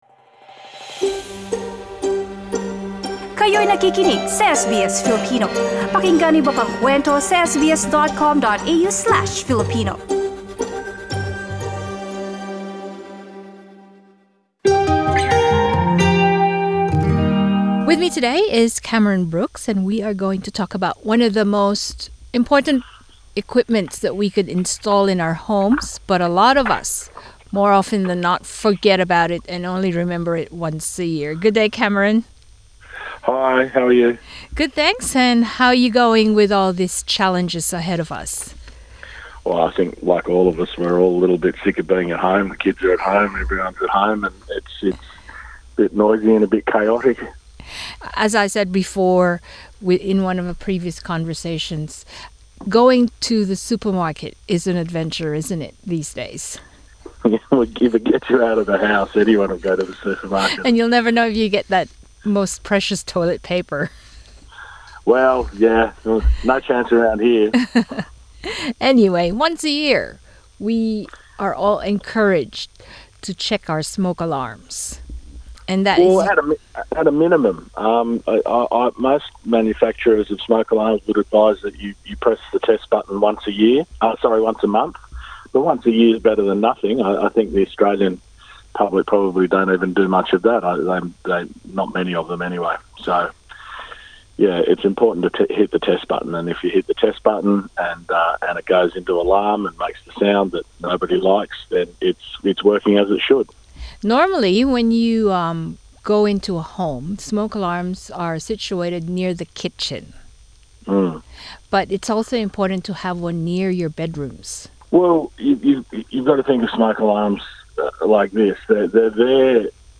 Pagsapit ng Linggo, ika -5 ng Abril magtatapos na ang DST (daylight saving time) ito magandang oportunidad upang i-check ang mga smoke alarm sa inyong mga bahay Pakinggan ang aming panayam Share